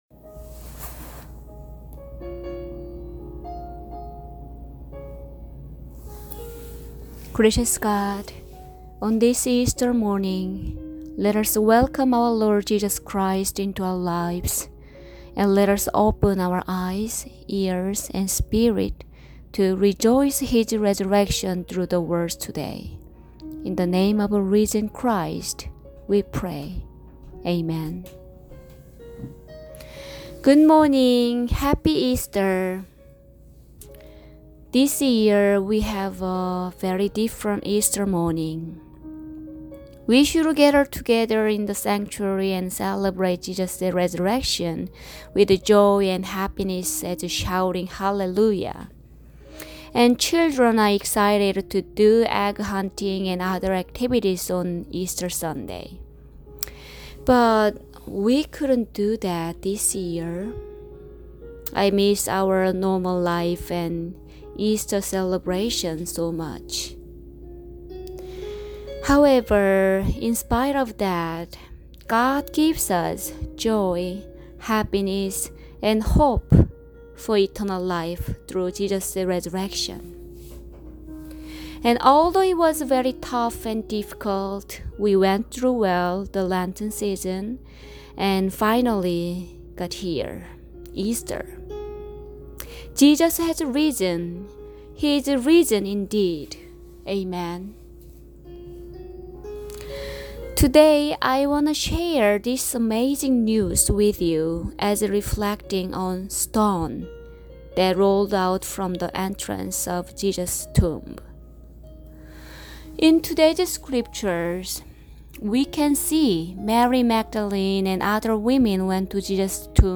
Preaching for Easter Sunday, April 12, 2020